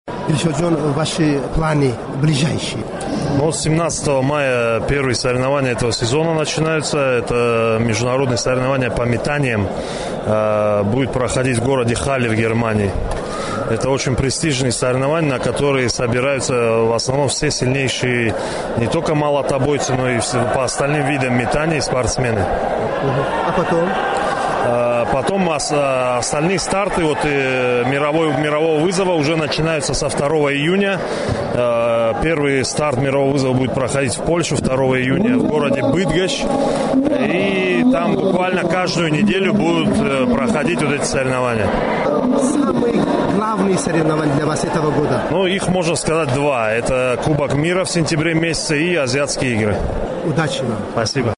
Гуфтугӯ бо Дилшод Назаров, варзишгари тоҷик